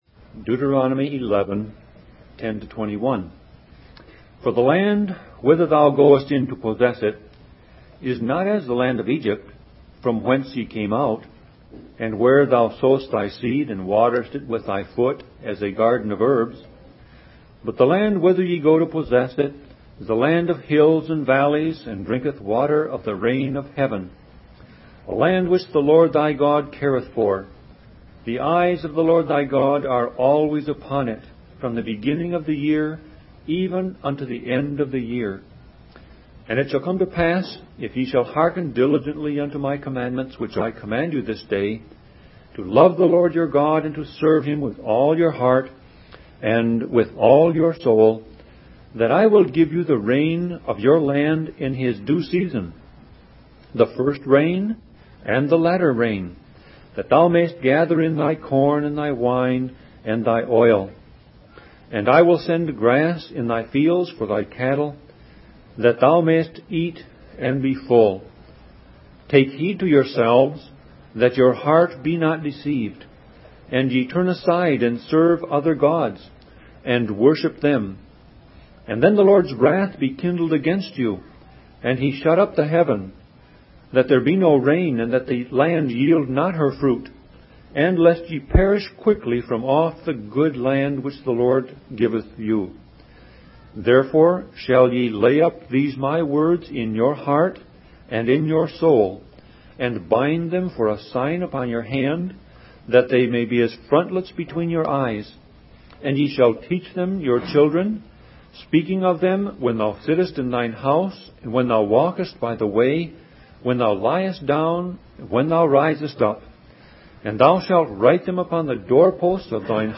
Sermon Audio Passage: Deuteronomy 11:10-21 Service Type